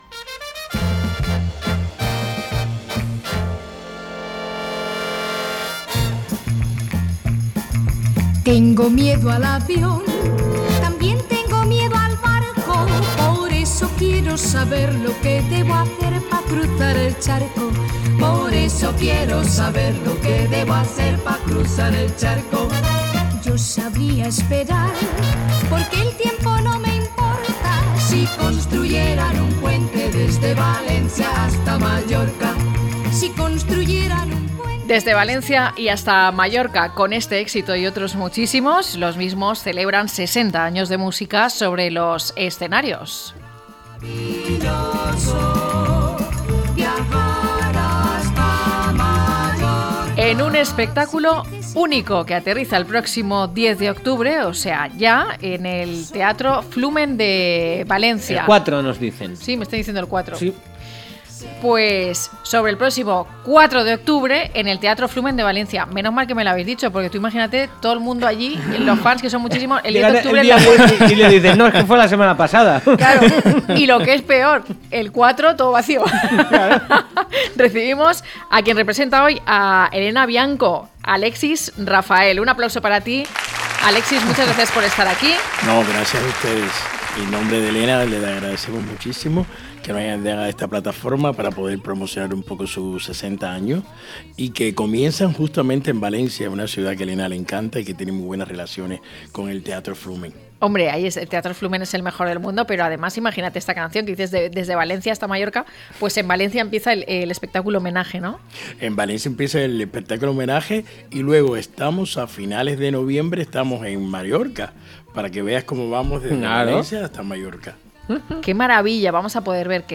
llega al estudio para hablar de Helena Bianco, la mítica voz de Los Mismos y ganadora de La Voz Senior